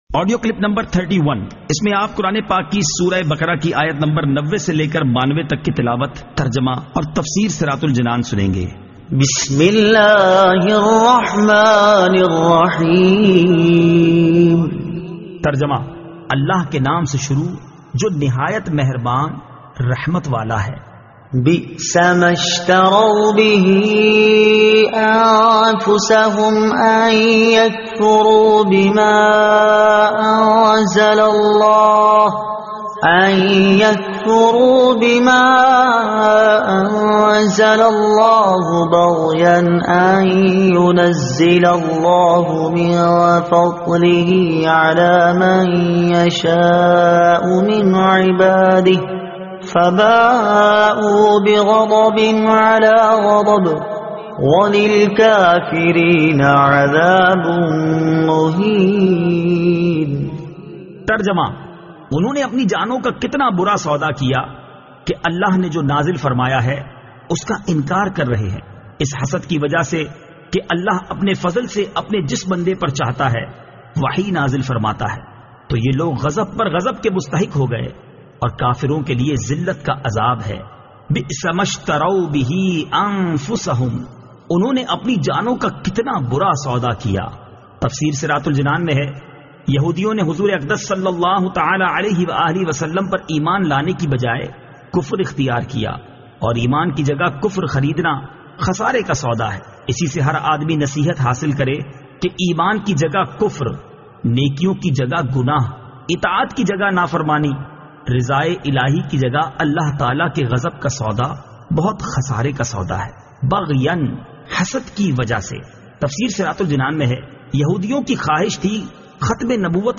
Surah Al-Baqara Ayat 90 To 92 Tilawat , Tarjuma , Tafseer